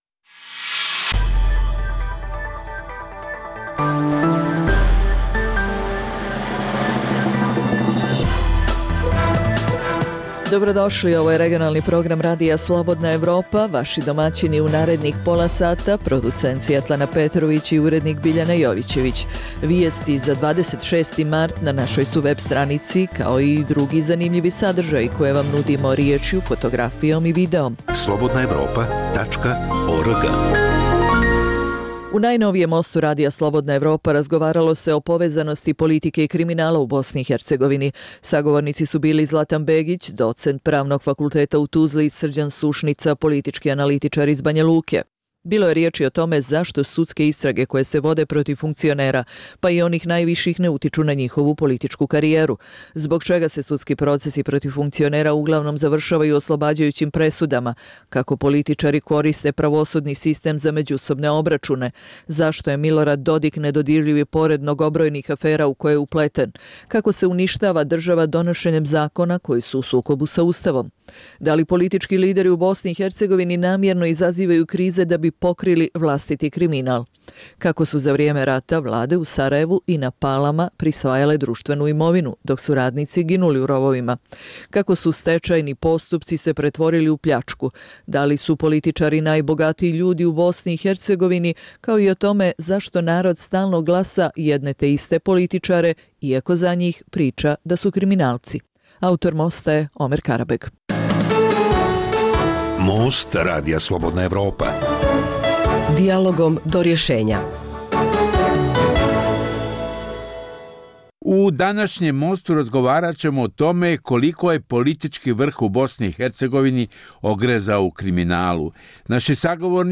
u kojem ugledni sagovornici iz regiona razmatraju aktuelne teme. Drugi dio emisije čini program "Pred licem pravde" o suđenjima za ratne zločine na prostoru bivše Jugoslavije.